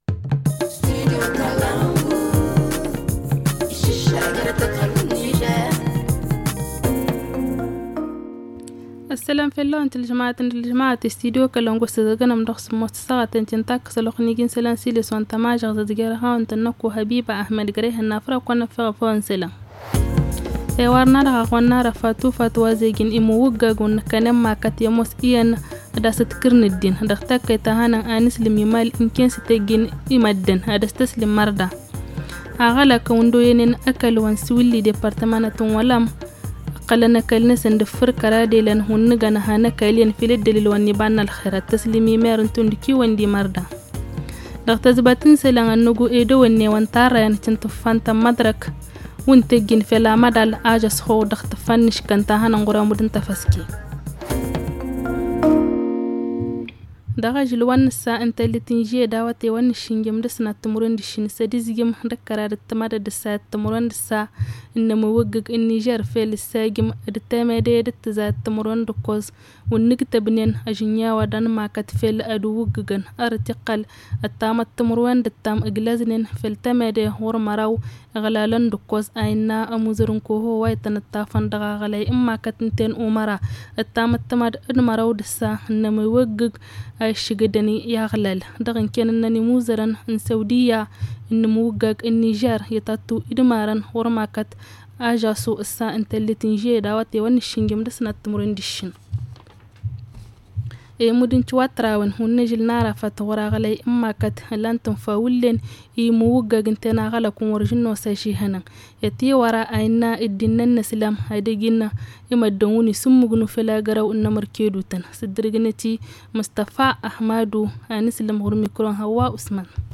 Le journal du 7 juillet 2022 - Studio Kalangou - Au rythme du Niger